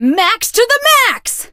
max_ulti_vo_04.ogg